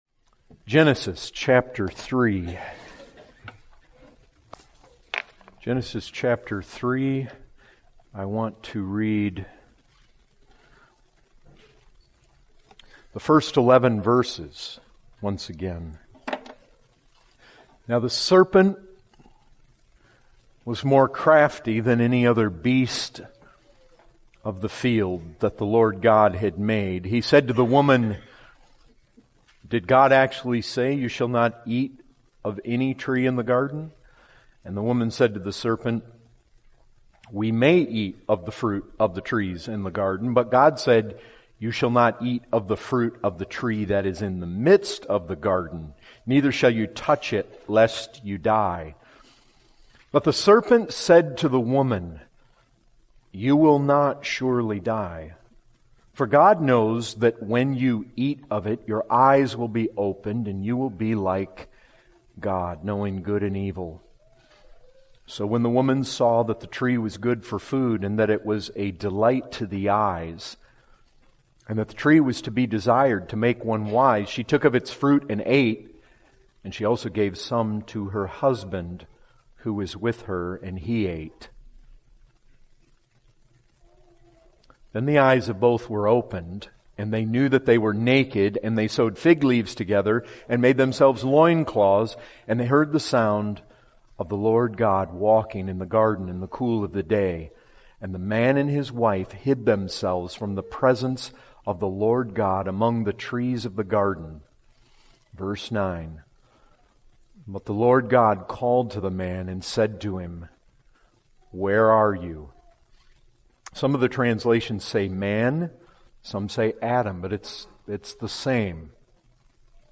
2019 Category: Full Sermons All of us